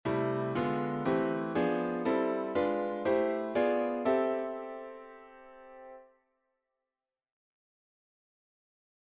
Improvisation Piano Jazz
Accord I6
En partant du Do à la basse, ça donne :